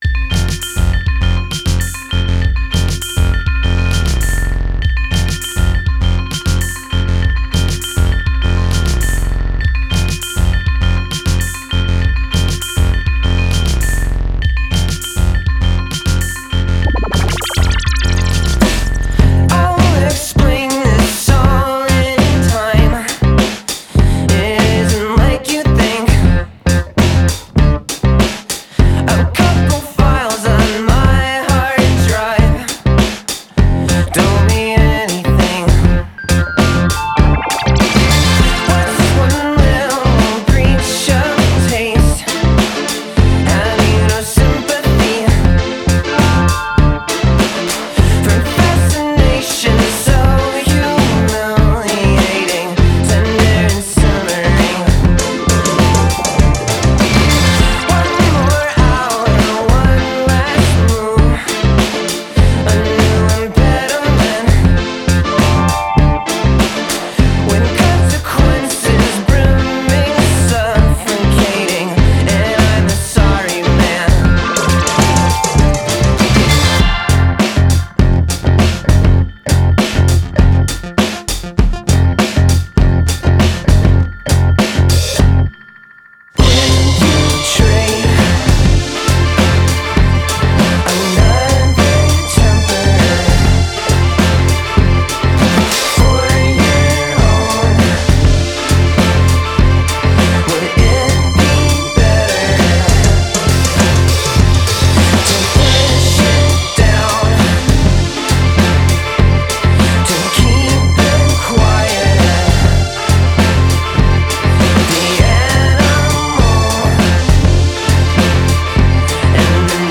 Recorded at Carefree Studios in Bushwick.
electric bass